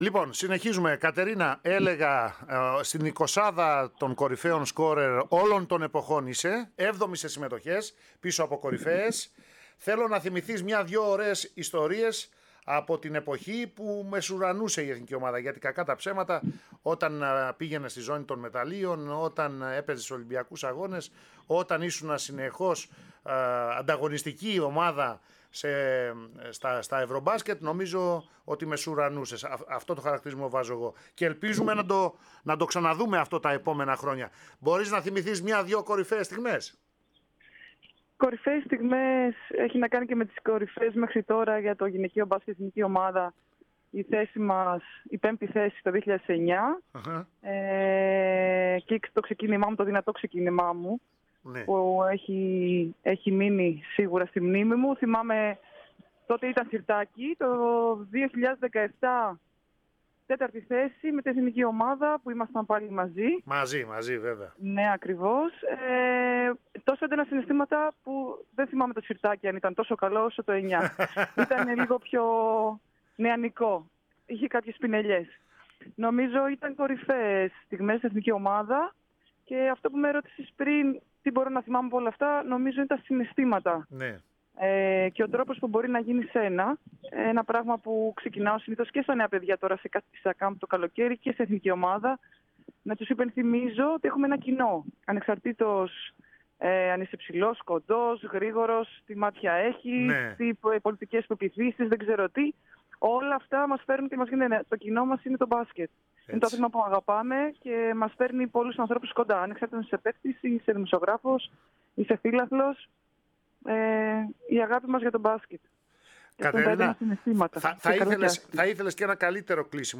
Η Κατερίνα Σωτηρίου ανακοίνωσε το τέλος της από Εθνική ομάδα με ανάρτηση στον προσωπικό της λογαριασμό. H 39χρονη αθλήτρια μίλησε στον αέρα της ΕΡΑ ΣΠΟΡ